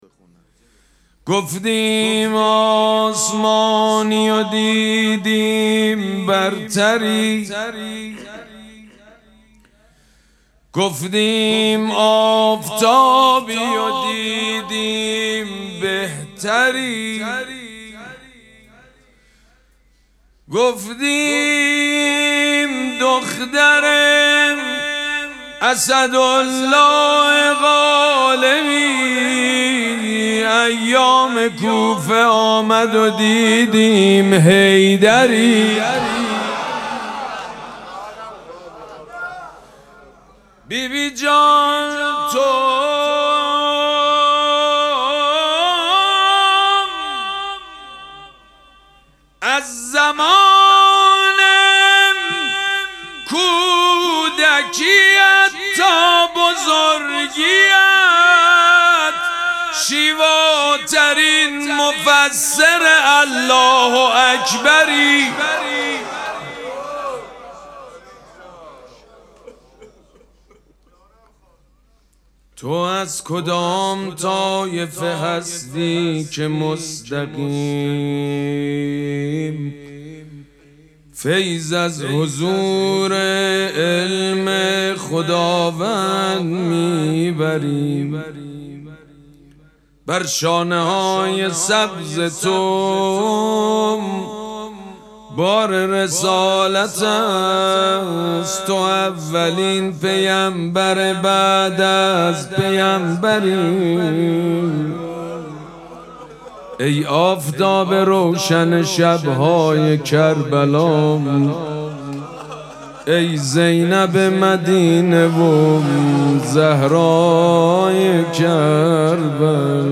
مراسم مناجات شب چهاردهم ماه مبارک رمضان
حسینیه ریحانه الحسین سلام الله علیها
مدح
حاج سید مجید بنی فاطمه